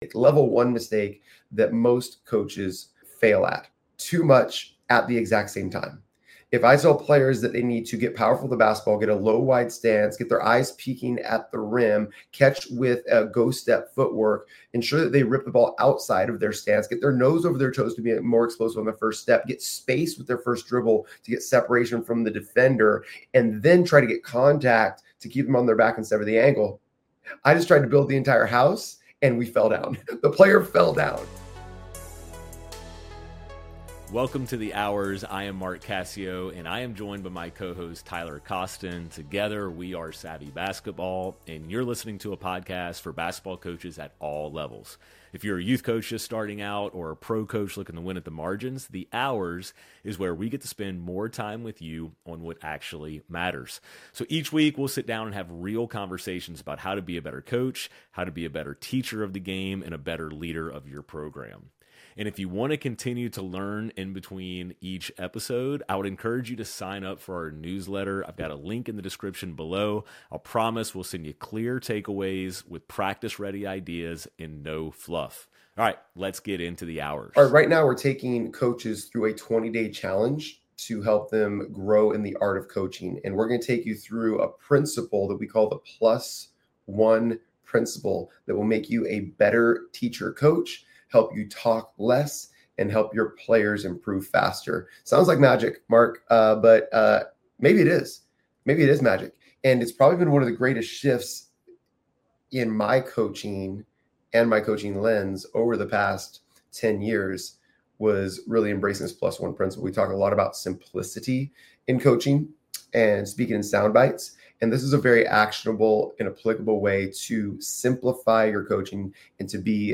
If you’ve ever: Overloaded your players with feedback Watched practice look good but games fall apart Felt like you’re “teaching” but players aren’t improving This conversation is for you.